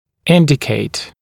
[‘ɪndɪkeɪt][‘индикейт]указывать, показывать, предписывать, свидетельствовать